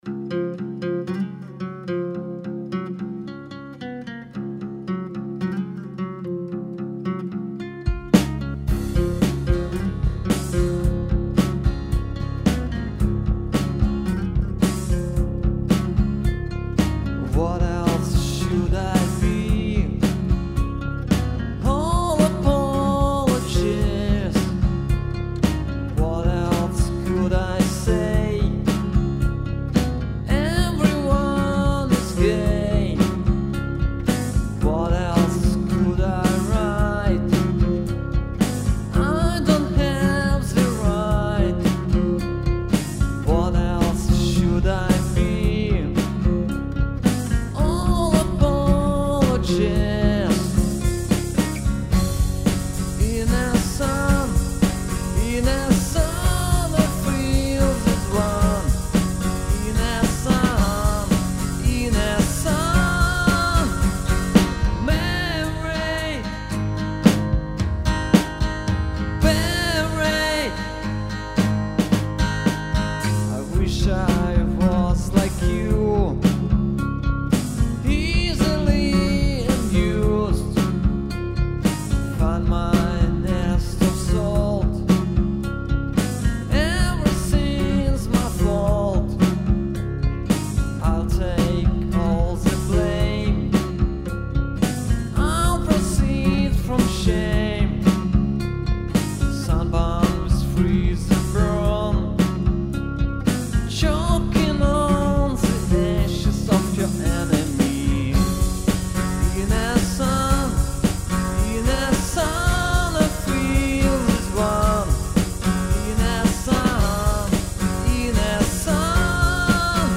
Искренне так, душевно.